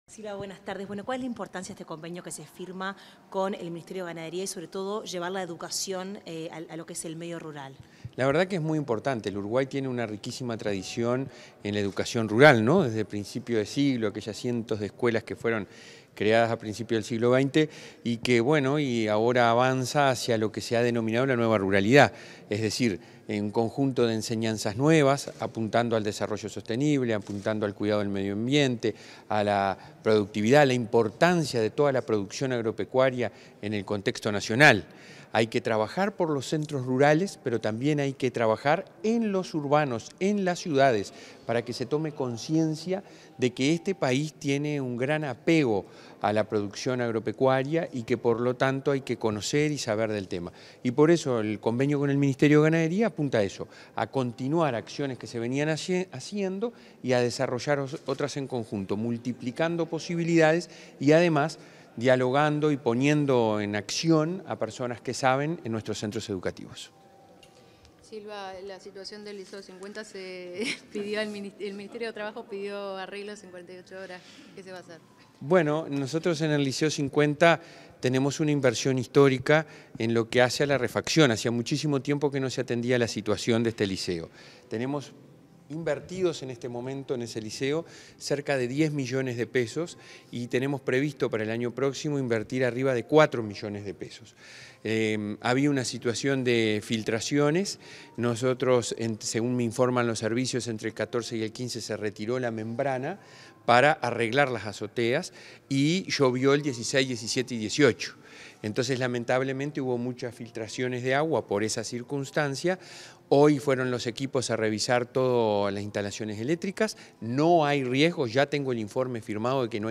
Declaraciones del presidente de ANEP, Robert Silva